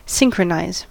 synchronize: Wikimedia Commons US English Pronunciations
En-us-synchronize.WAV